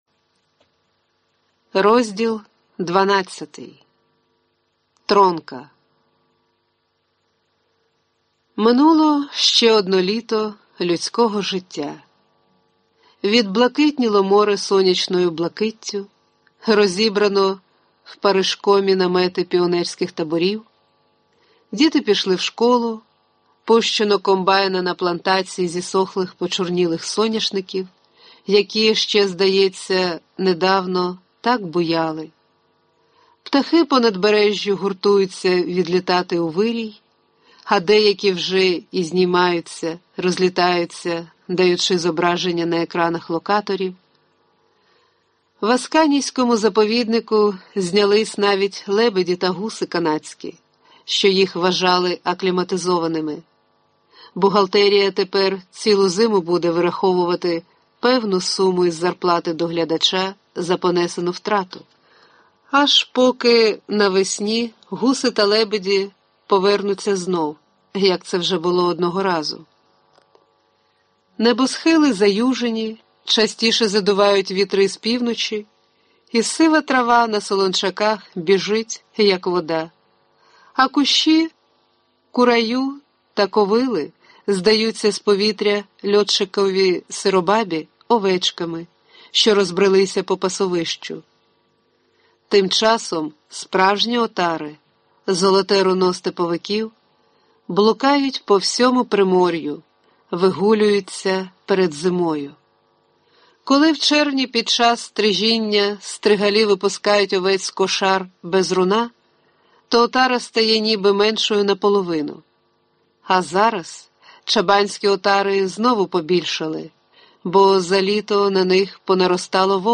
Аудиокнига Тронка. Новела | Библиотека аудиокниг